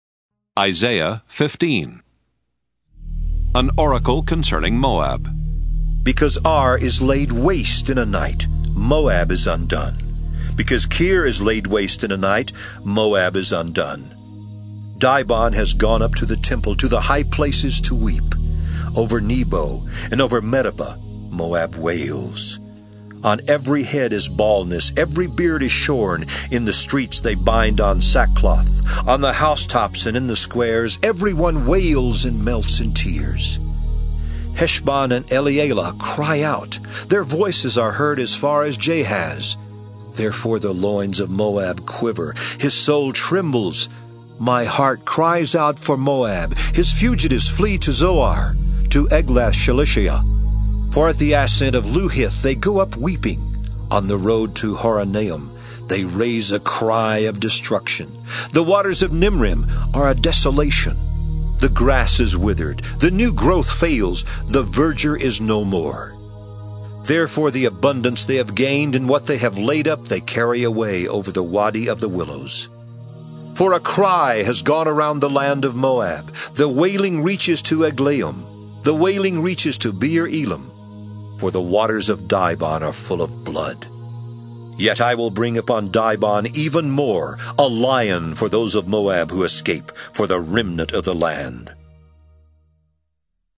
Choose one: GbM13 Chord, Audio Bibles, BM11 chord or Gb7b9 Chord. Audio Bibles